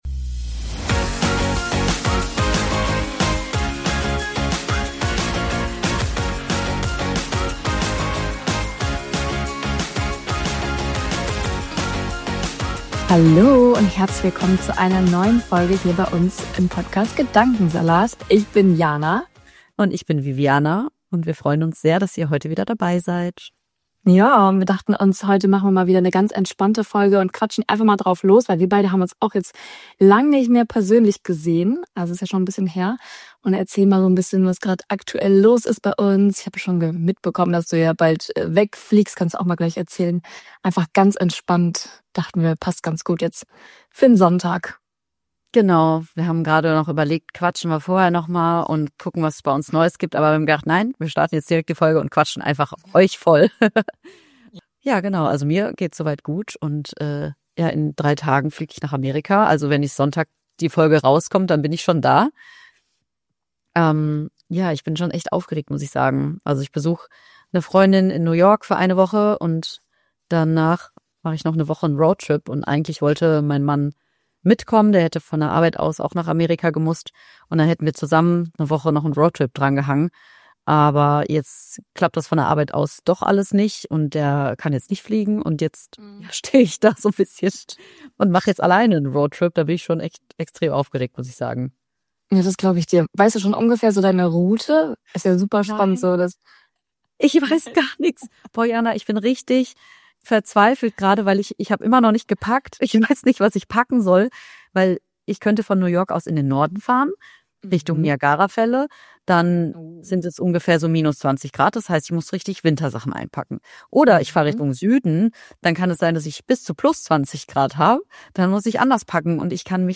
Eine entspannte, ehrliche Laber-Folge über Fernweh, Wandel und kleine Beobachtungen aus unserem Alltag.